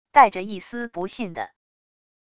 带着一丝不信的wav音频